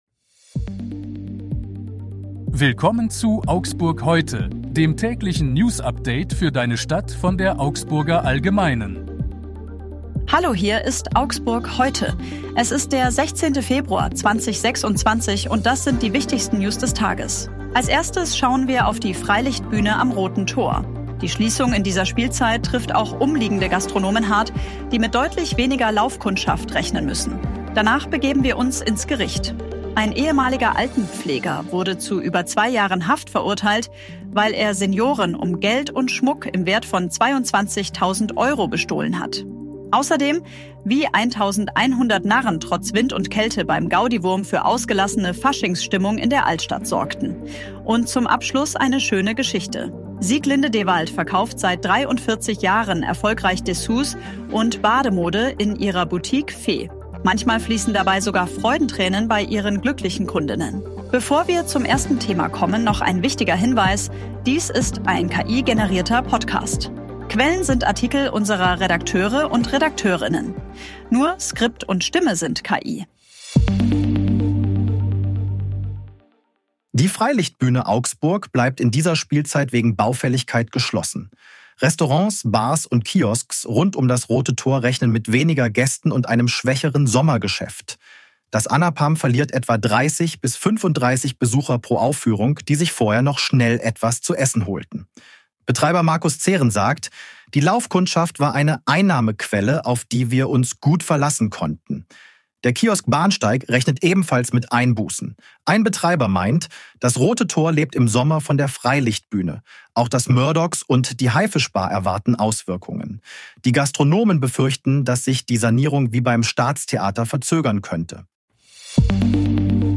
Skript und Stimme sind KI.